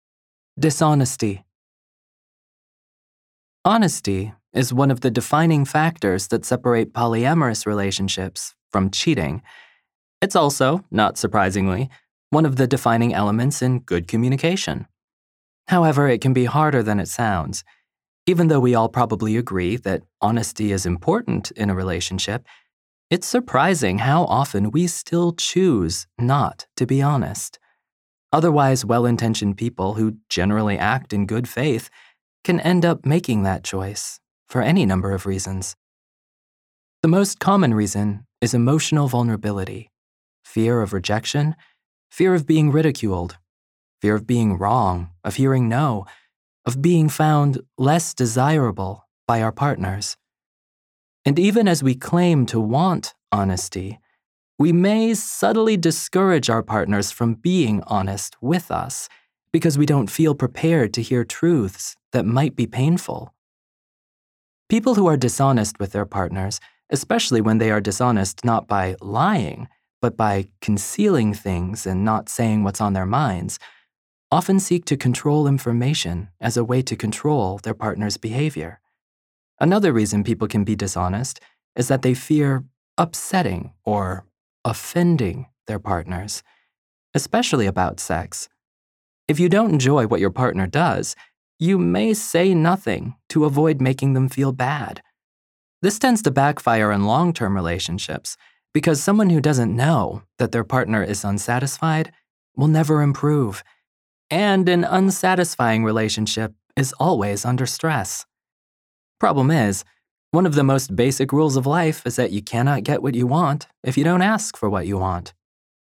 Non-Binary, conversational  |  download